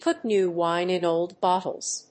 アクセントpùt néw wíne in óld bóttles